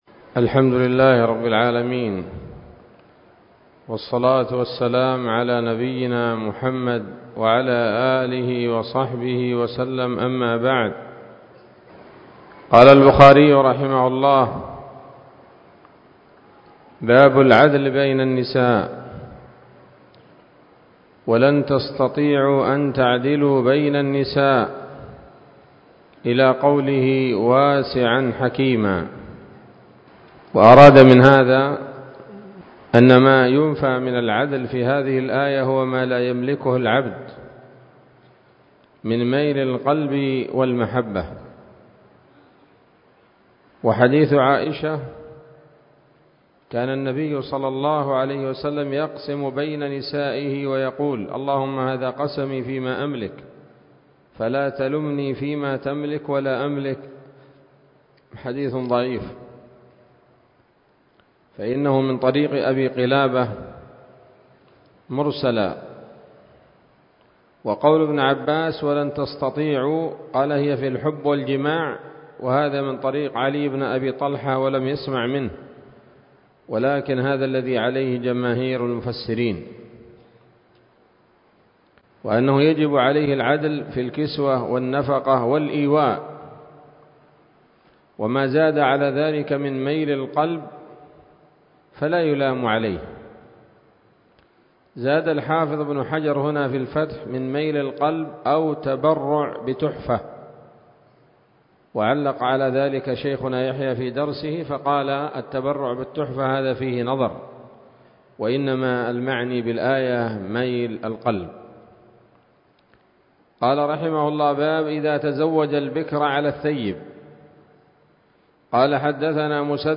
الدرس الحادي والثمانون من كتاب النكاح من صحيح الإمام البخاري